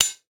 hoverMenuButton.wav